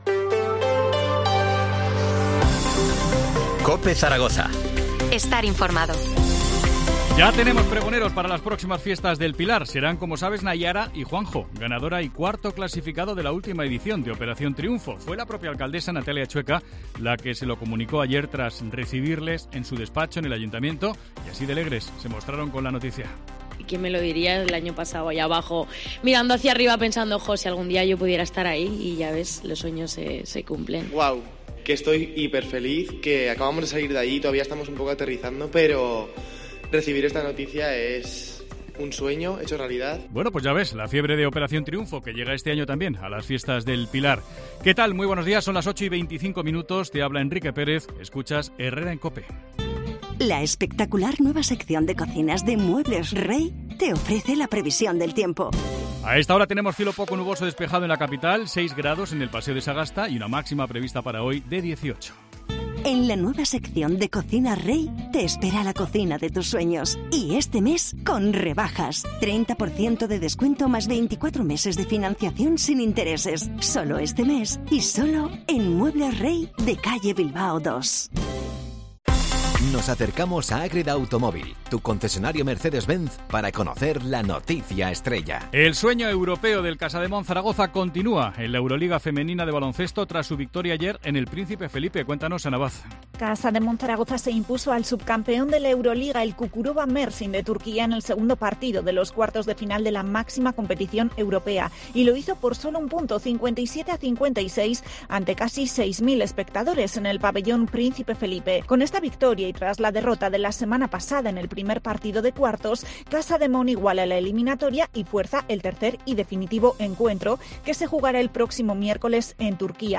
AUDIO: Titulares del día en COPE Zaragoza